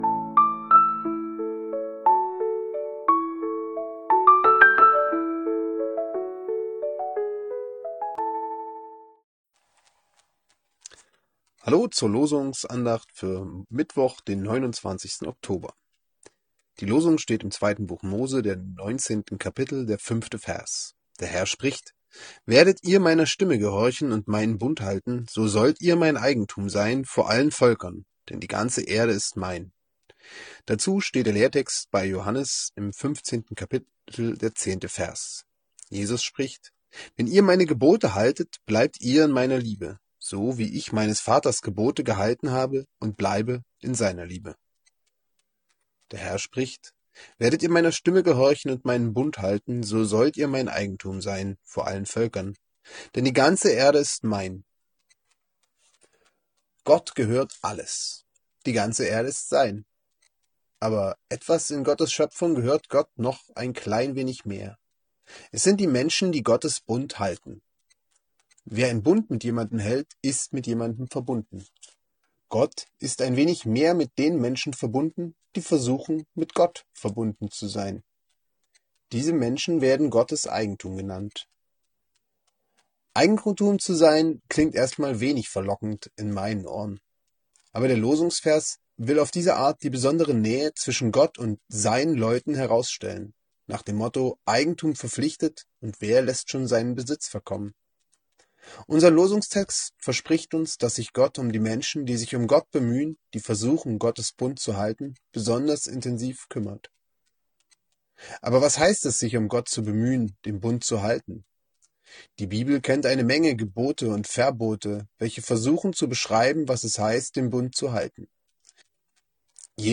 Losungsandacht für Mittwoch, 29.10.2025 – Prot.